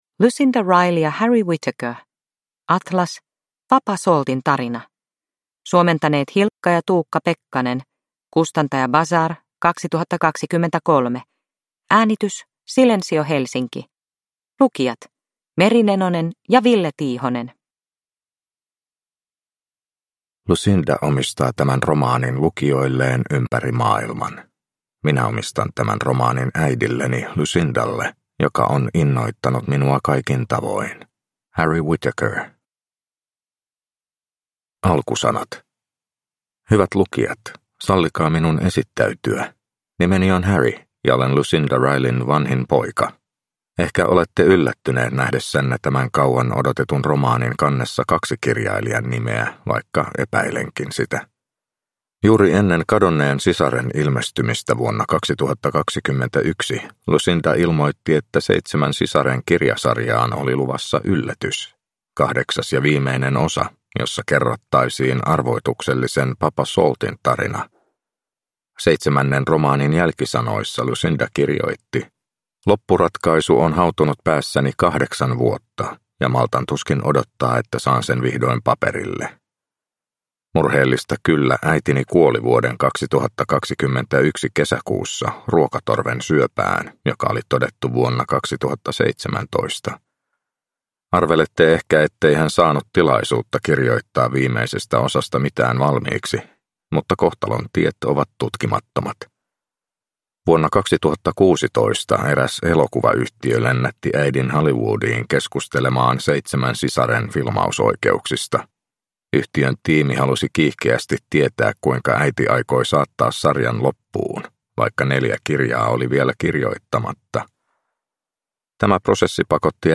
Atlas, Papa Saltin tarina – Ljudbok – Laddas ner